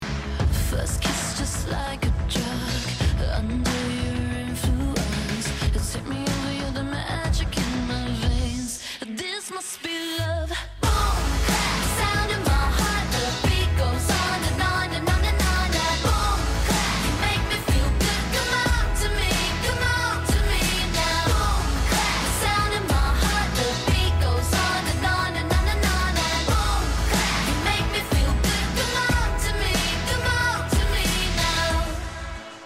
Tag       R&B R&B